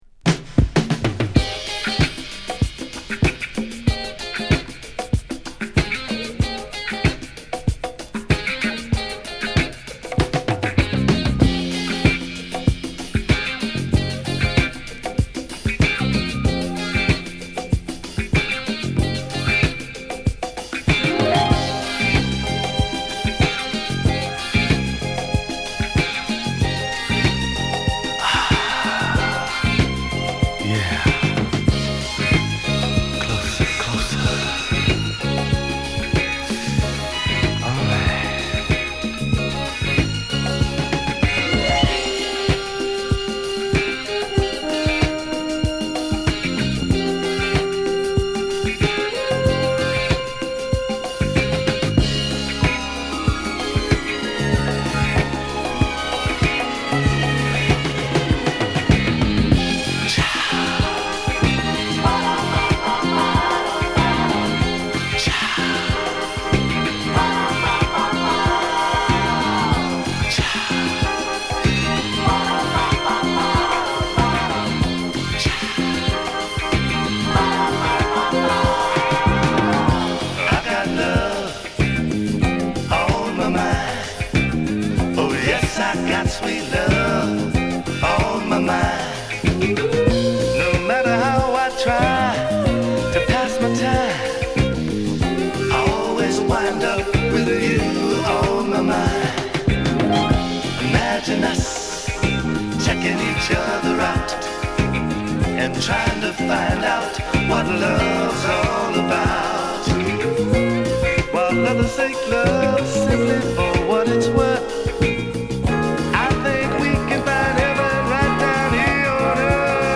はヴィブラフォンも気持ち良いです。